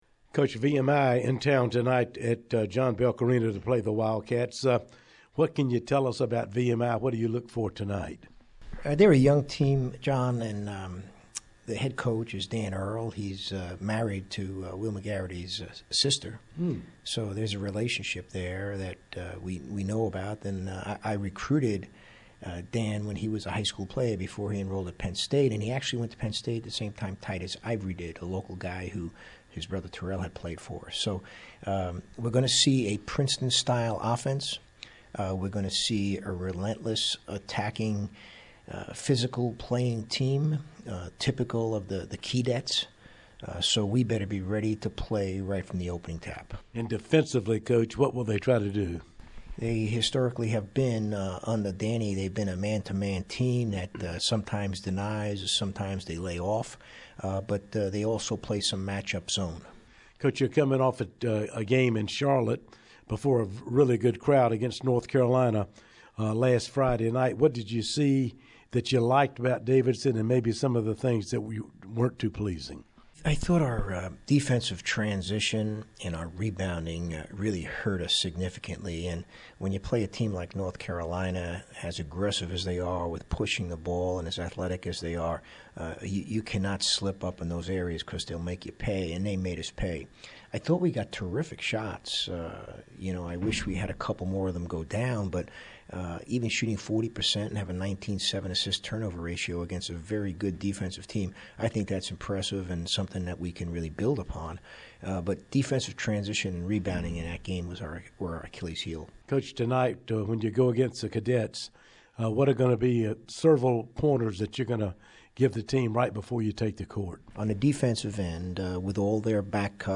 Pregame Interview